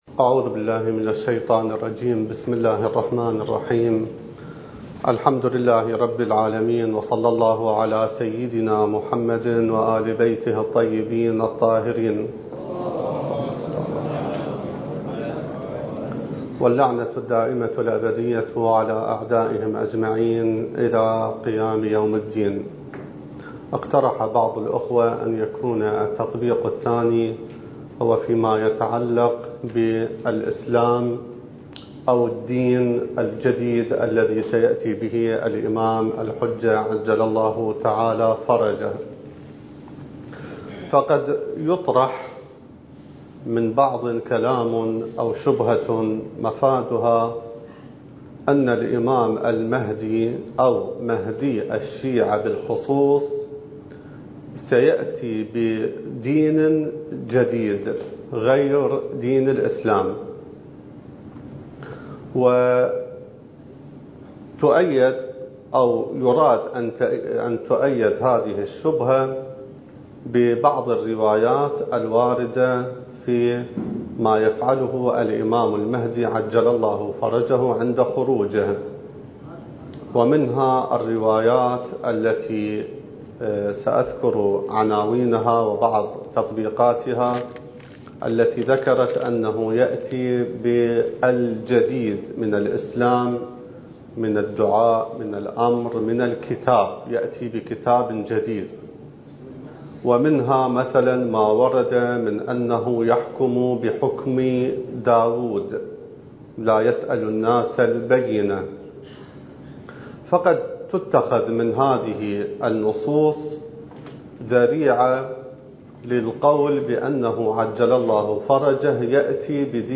الدورة المهدوية الأولى المكثفة (المحاضرة الرابعة والثلاثون)